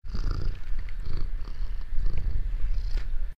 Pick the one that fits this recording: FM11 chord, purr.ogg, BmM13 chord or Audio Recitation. purr.ogg